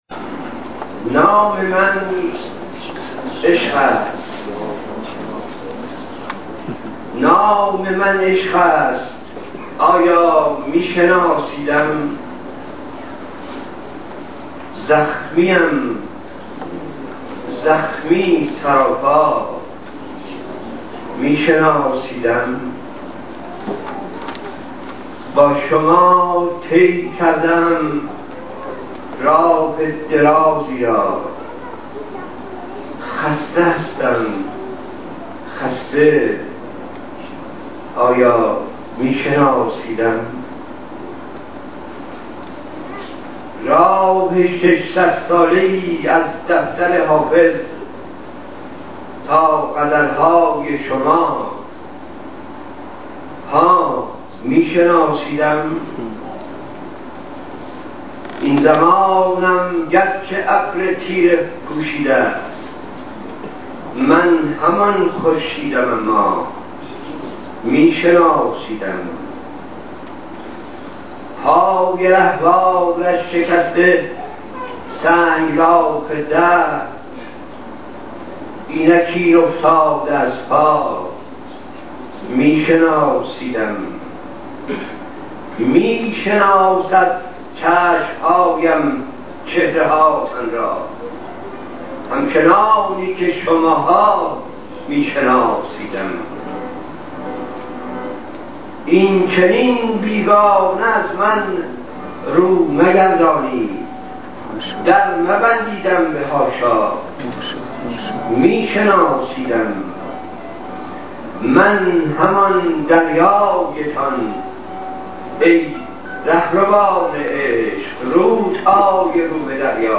شعرخوانی حسین منزوی <